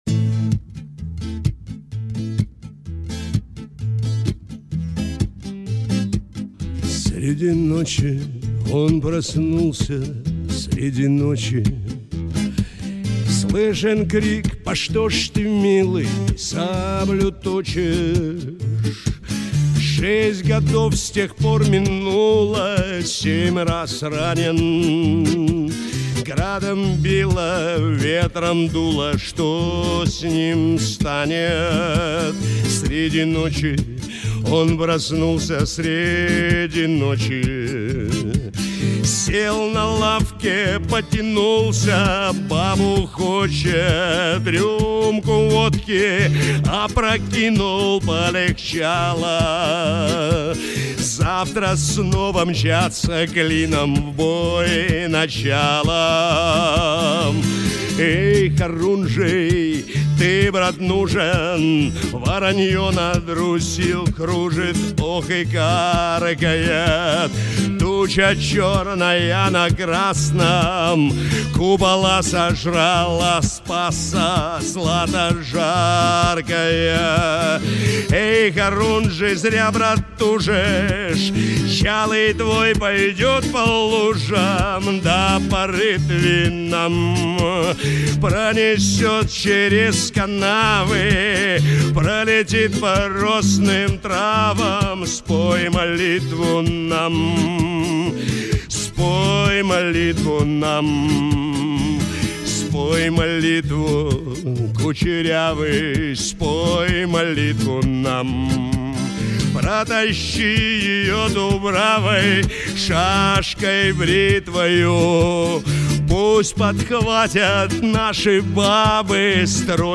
Шансон под гитару